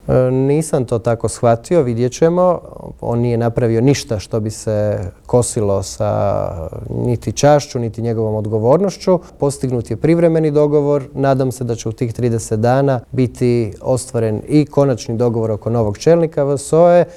Otkrio nam je u Intervjuu tjedna Media servisa uoči održavanja sjednice Odbora za gospodarstvo upravo na temu prodaje plina višestruko ispod tržišne cijene.